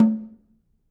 Snare2-HitNS_v3_rr2_Sum.wav